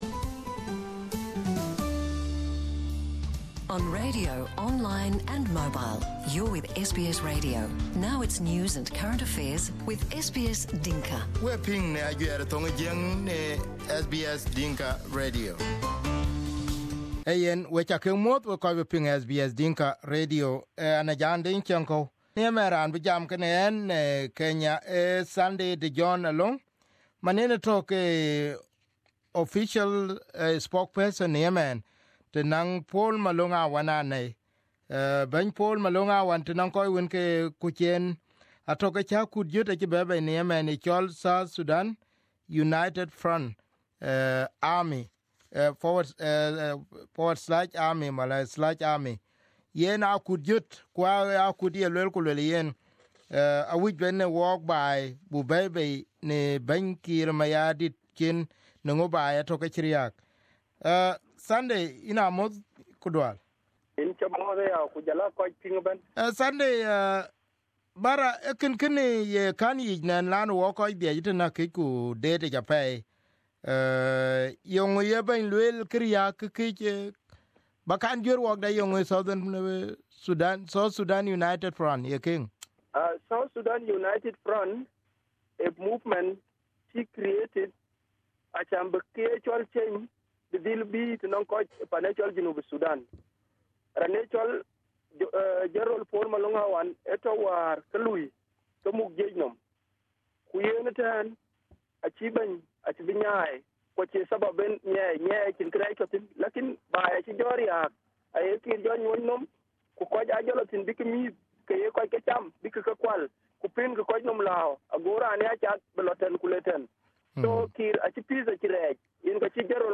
In his first interview on SBS Dinka Radio